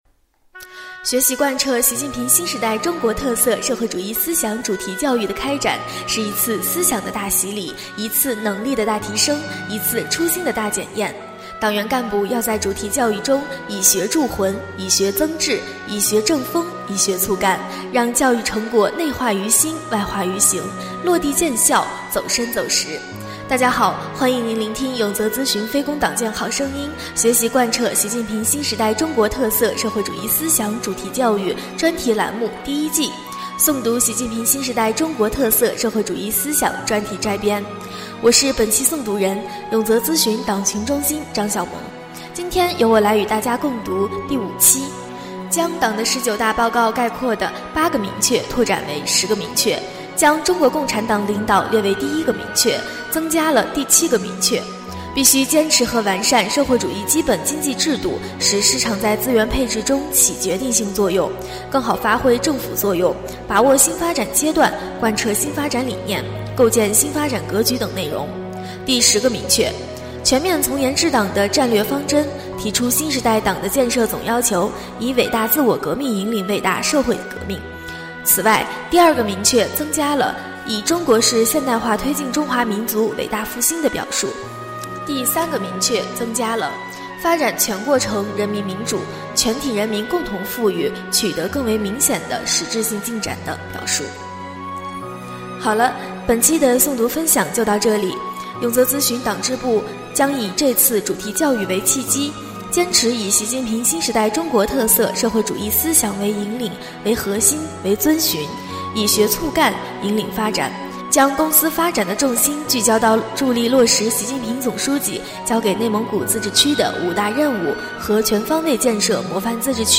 【诵读】《习近平新时代中国特色社会主义思想专题摘编》第5期-永泽党建